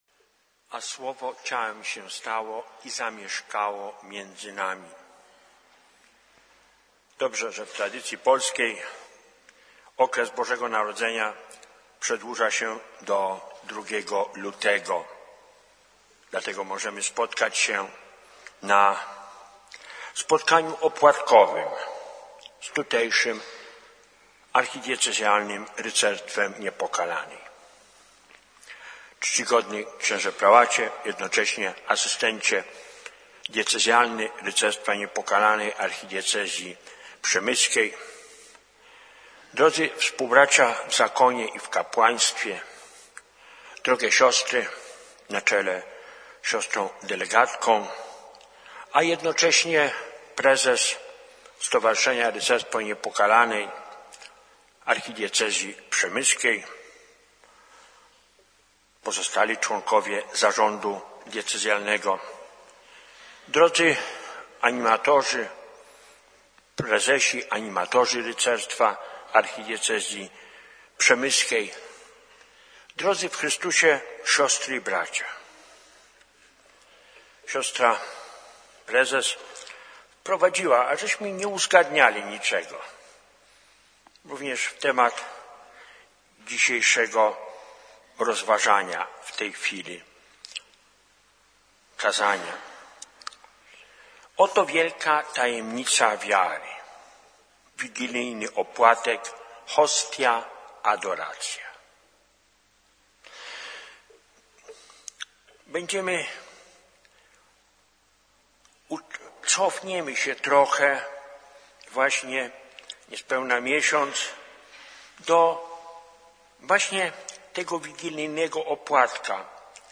W Strachocinie w dniu 19 stycznia 2020 roku, odbyło się spotkanie opłatkowe dla Rycerzy Niepokalanej Archidiecezji Przemyskiej.